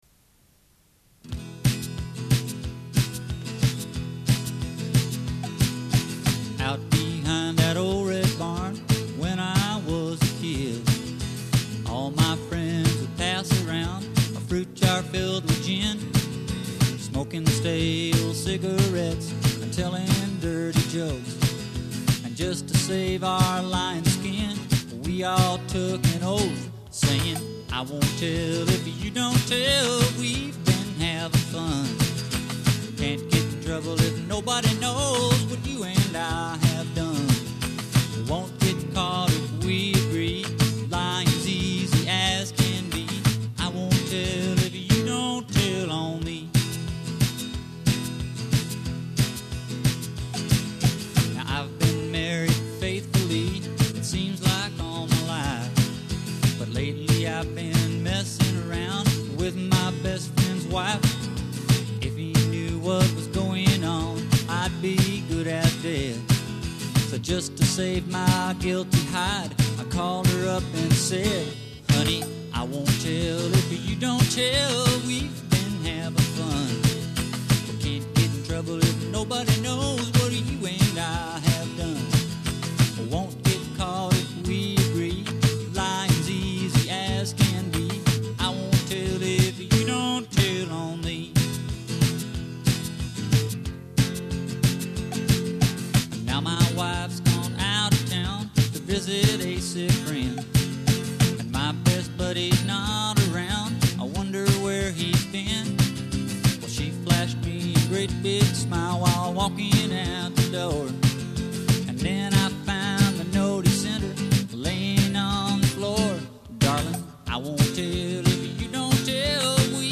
CASSETTE DEMO